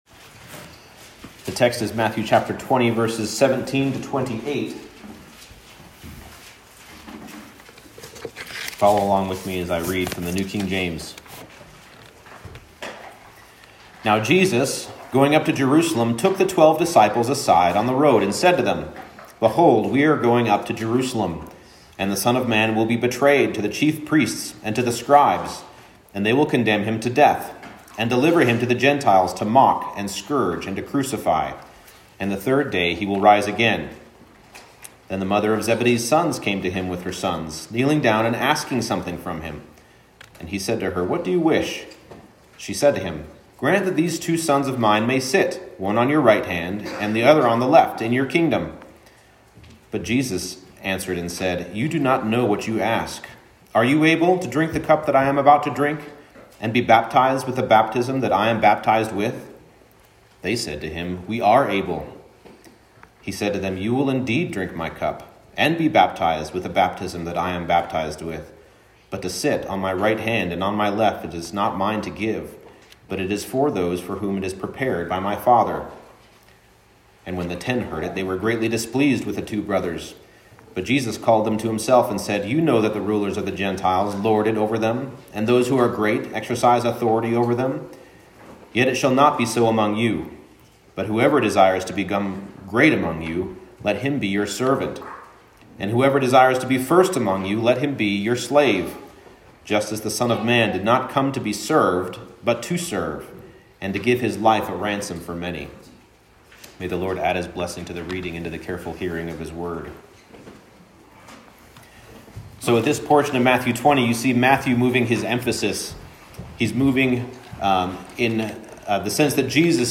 Matthew 20:17-28 Service Type: Morning Service Christ showed greatness by coming as the Son of Man to serve as a ransom.